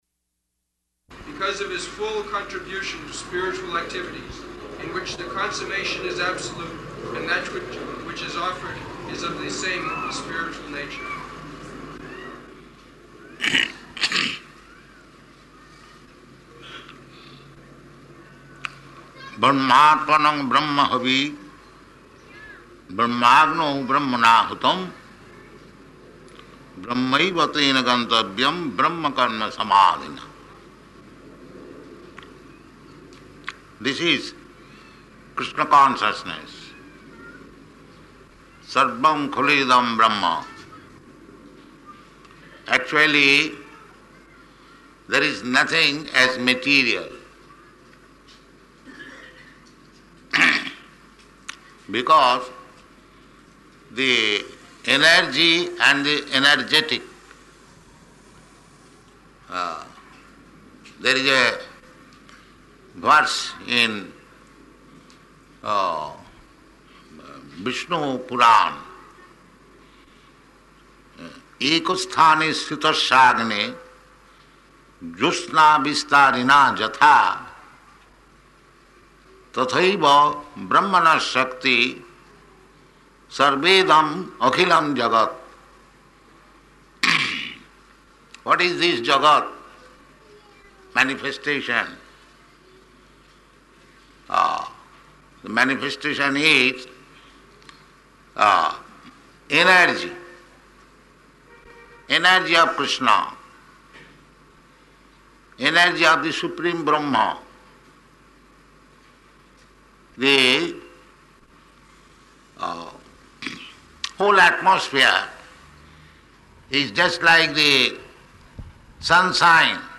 Type: Bhagavad-gita
Location: Bombay